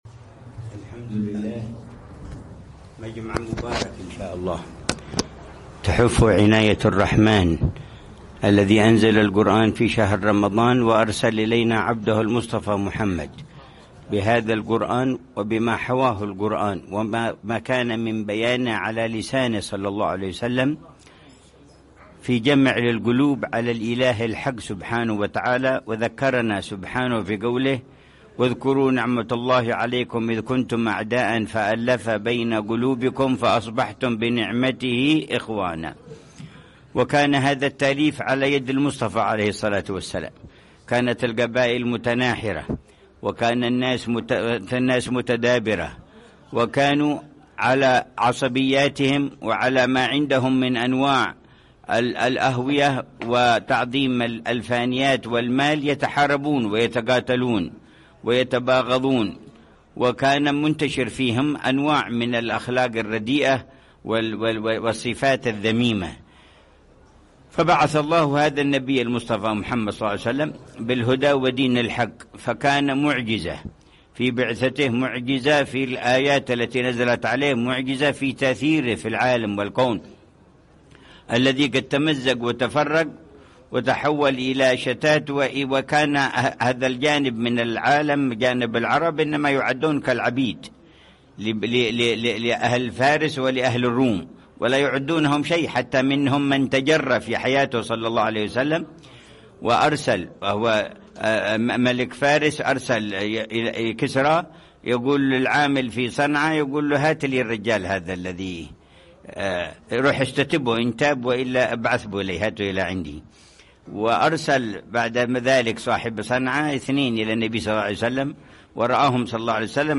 مذاكرة